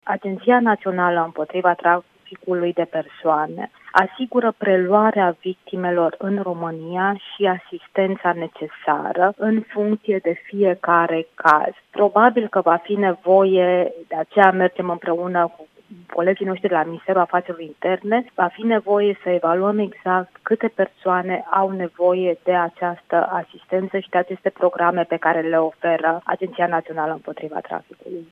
Într-un interviu acordat Europa FM, ministrul pentru Românii de Pretutindeni spune că la Ministerul de Interne s-a constituit o celulă la care lucrează specialiști români și italieni.